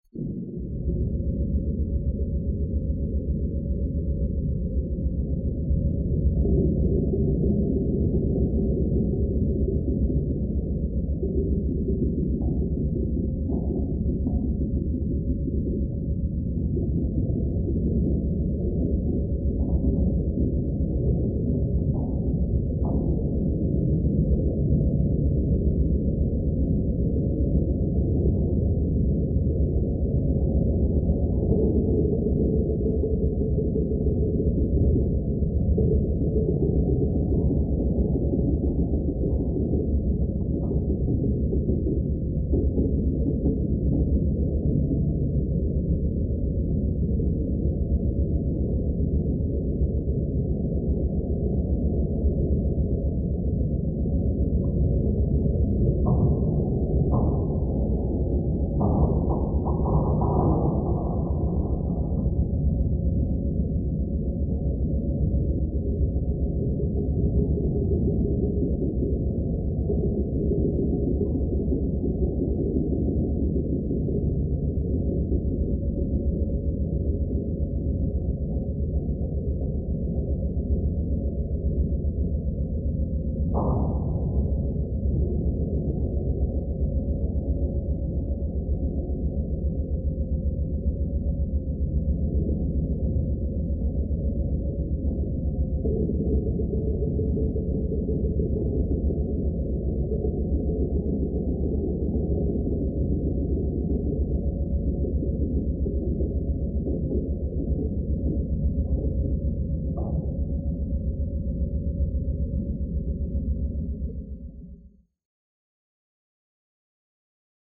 Гул со скрипами и стуком
Тут вы можете прослушать онлайн и скачать бесплатно аудио запись из категории «Звуковые эффекты».